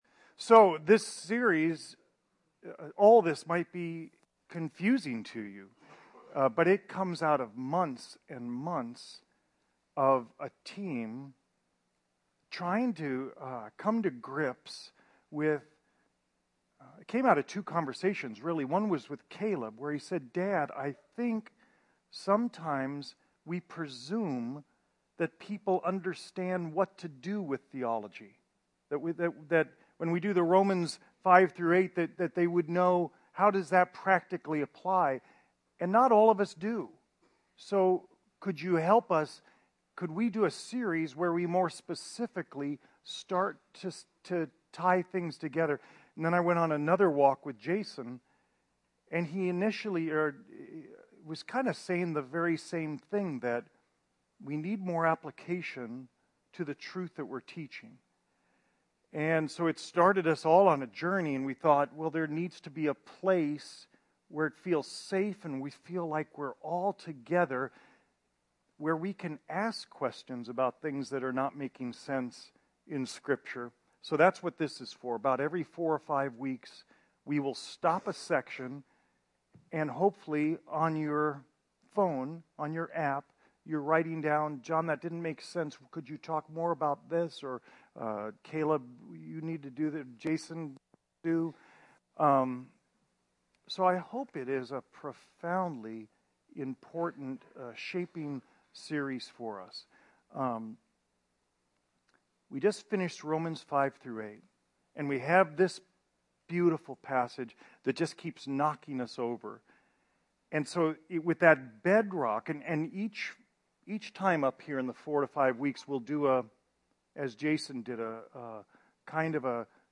In my closest relationships when things get sideways? 1 B Podcast Podcaster Open Door Fellowship Church Phoenix, AZ You are listening to an audio recording of Open Door Fellowship Church in Phoenix, Arizona.